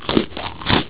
munching.au